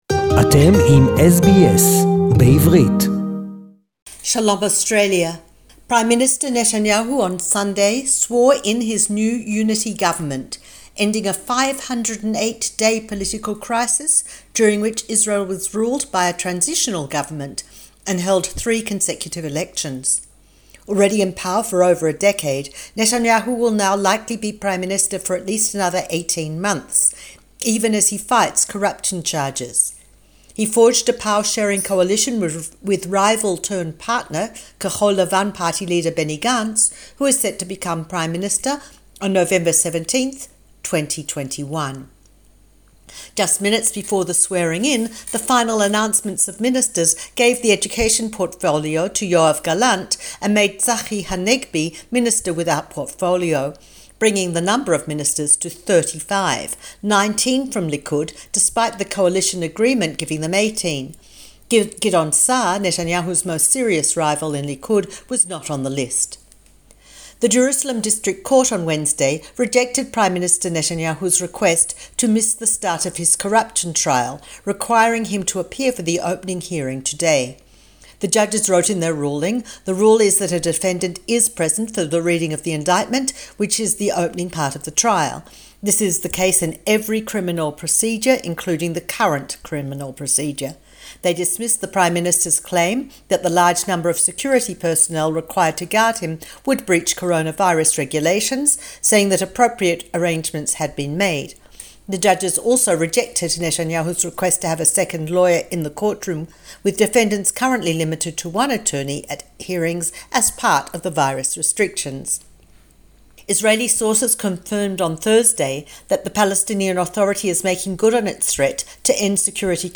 Israel's PM Netanyahu, unbeaten in elections, goes on trial today, SBS Jerusalem report 24.5.2020